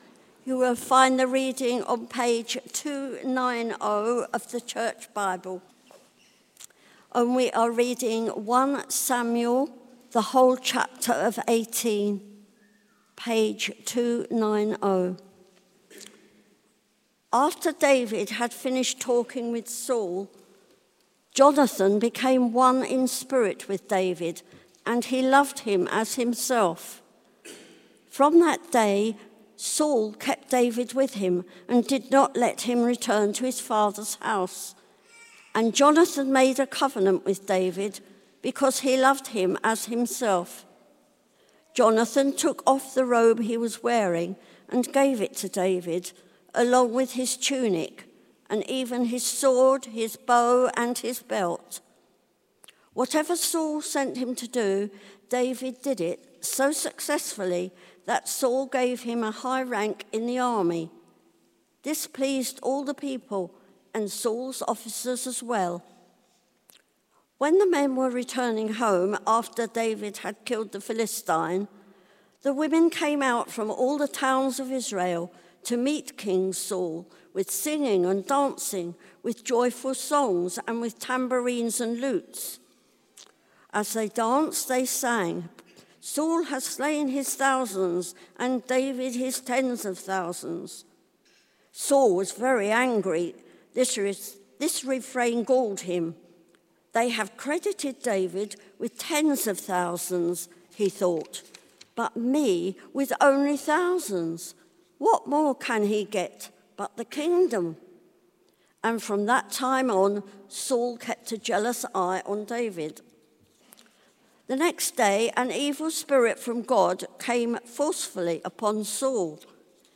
Series: The King after God's own Heart Theme: Friendship and Jealousy: The Bond and Rift between David and Sau Sermon